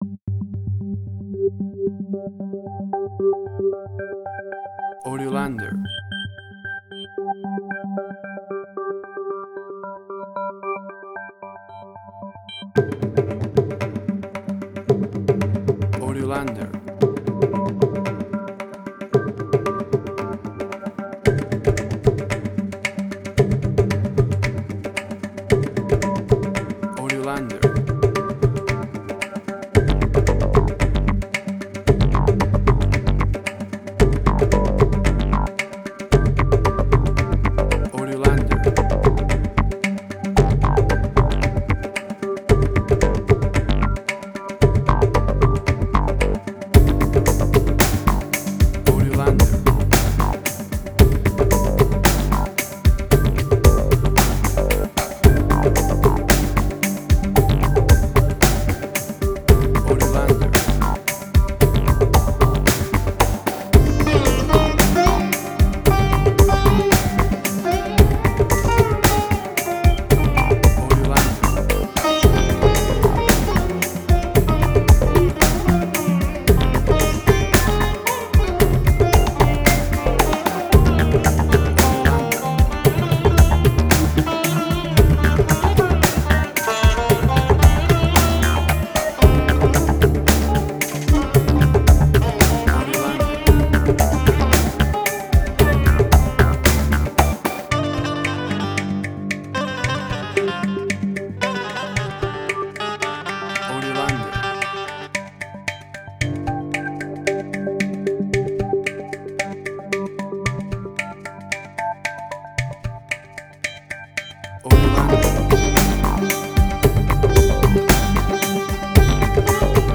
Indian Fusion
Tempo (BPM): 113